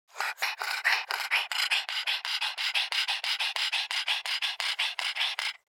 دانلود آهنگ سگ 12 از افکت صوتی انسان و موجودات زنده
دانلود صدای سگ 12 از ساعد نیوز با لینک مستقیم و کیفیت بالا
جلوه های صوتی